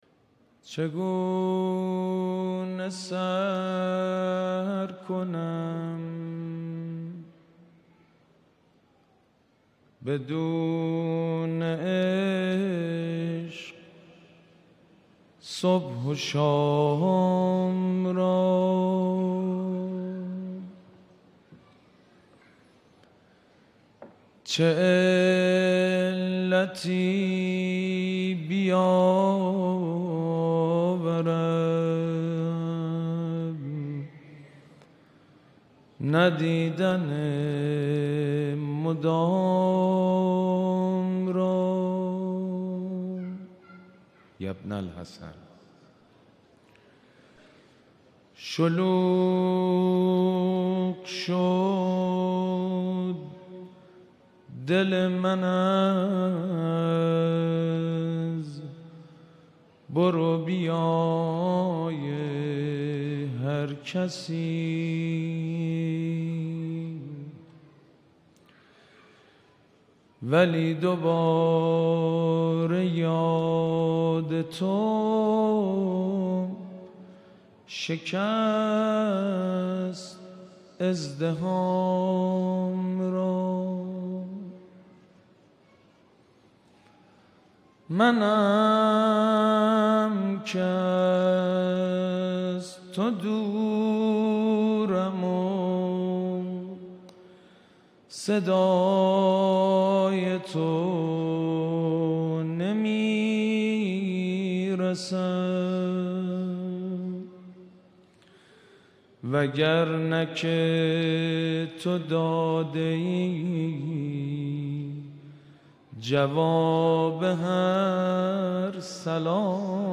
دومین شب از مراسم عزاداری حضرت اباعبدالله الحسین علیه‌السلام
عزاداری شب هشتم محرم و توسل به حضرت علی‌اکبر‌ علیه‌السلام
مداحی